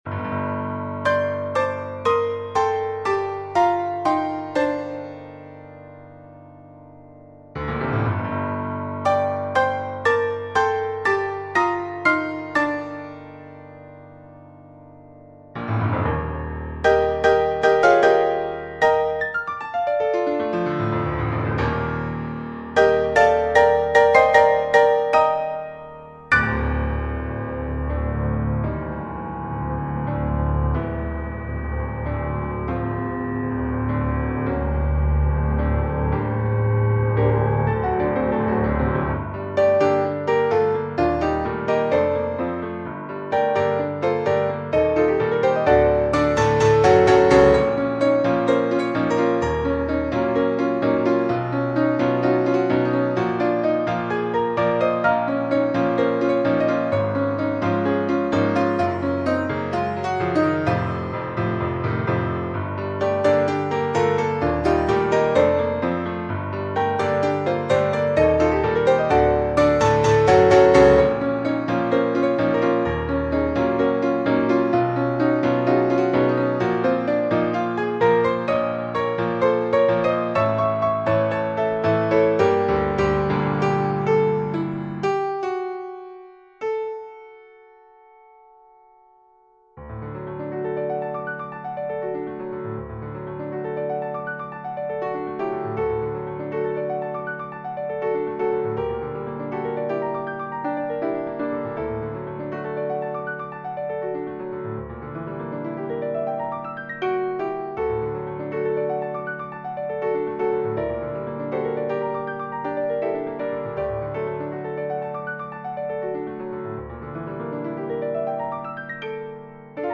SSA female choir and piano
世俗音樂